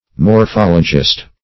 Morphologist \Mor*phol"o*gist\, n. (Biol.)